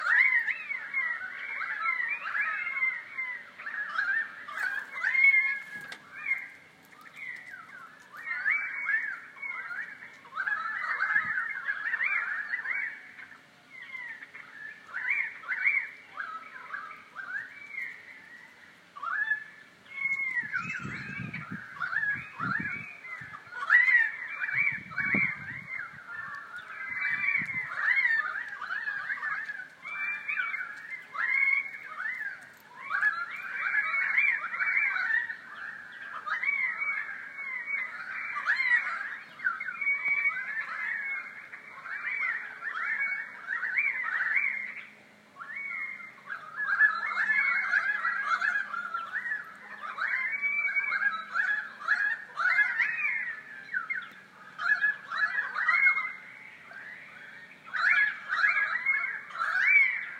Many had suggested the word I had in mind – cacophony, because when they get together currawongs make a racket (listen to audio clip below).
pied-currawong.m4a